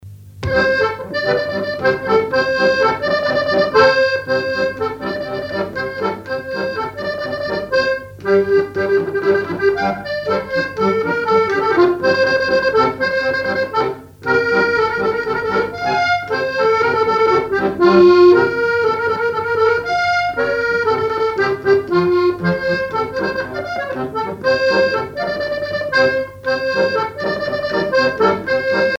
Répertoire sur accordéon chromatique
Pièce musicale inédite